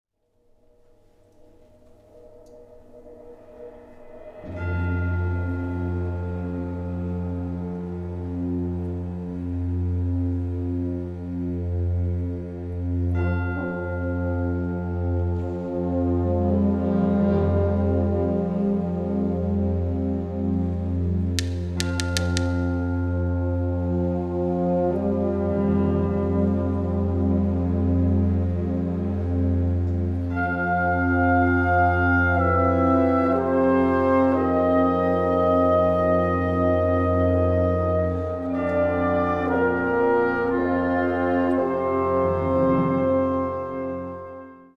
Gattung: Konzertstück
Besetzung: Blasorchester
Ruhig klingt das Stück in der anfänglichen Stimmung aus.